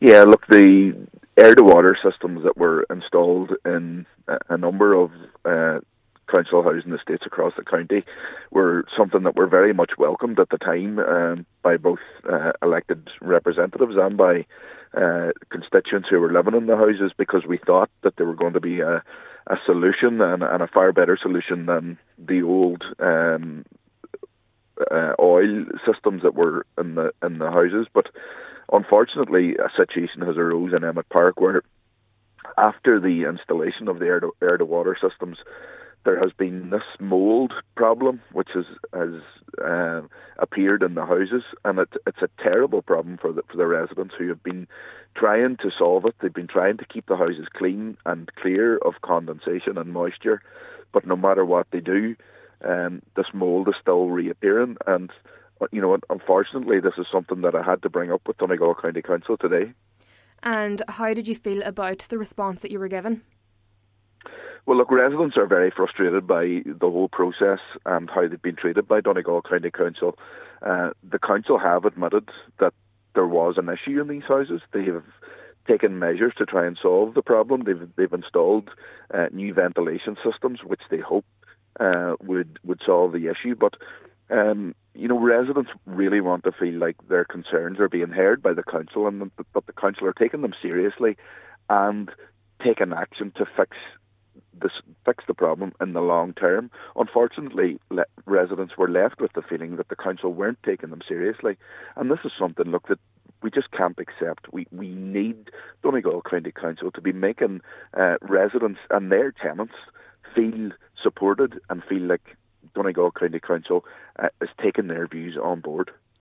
Councillor Gary Doherty has hit out at the local authority’s response: